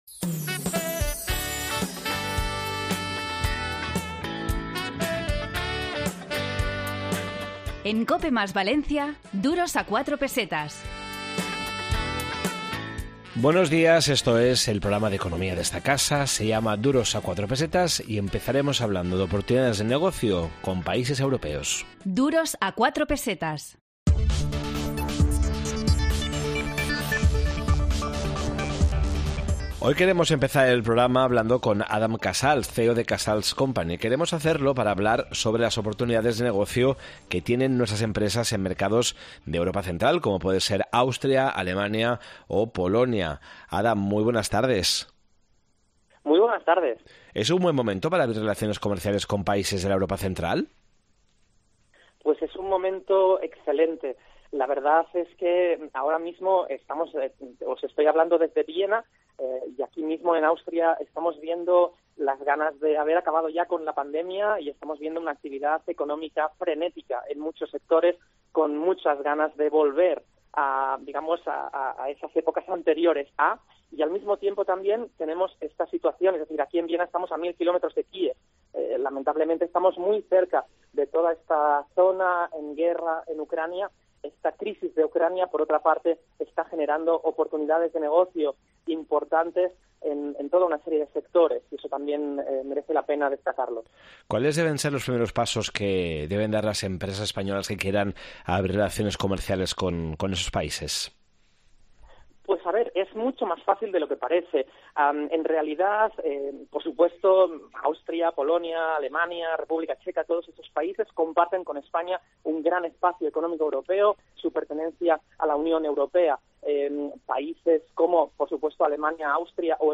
Esta semana, en Duros a 4 Pesetas de COPE + Valencia, en el 92.0 de la FM, hemos preparado un programa dedicado a la apertura de un nuevo hotel en Valencia, las relaciones comerciales con países de Europa central y la responsabilidad civil en espacios lúdicos privados y en supermercados.